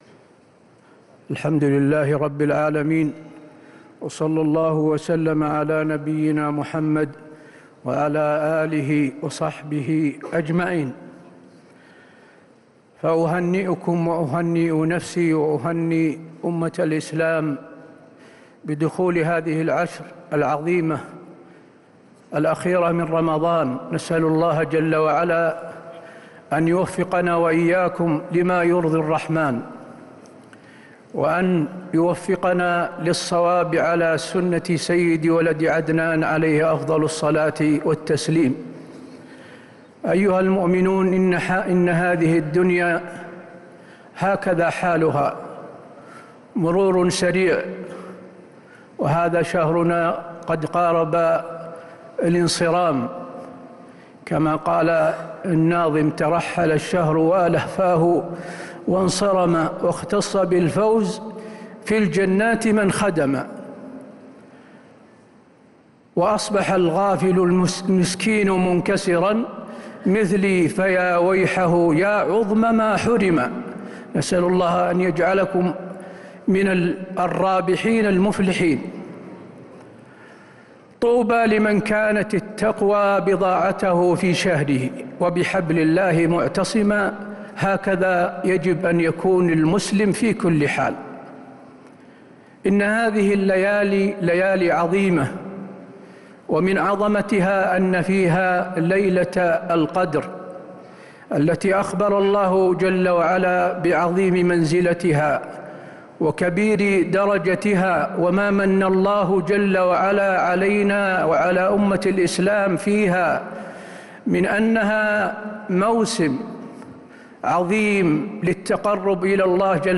كلمة الشيخ حسين آل الشيخ بمناسبة حلول العشر الأواخر بعد صلاة العشاء 20 رمضان 1447هـ > كلمات أئمة الحرم النبوي 🕌 > المزيد - تلاوات الحرمين